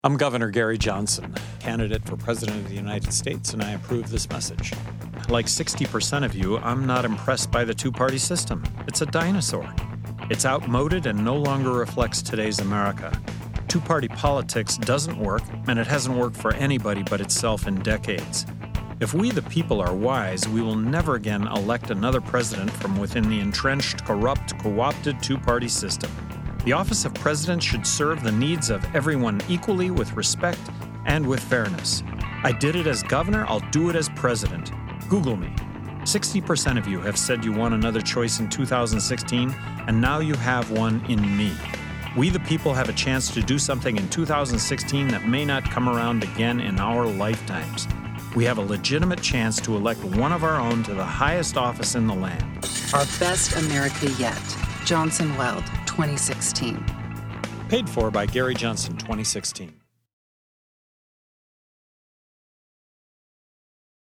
Where I found that radio spot.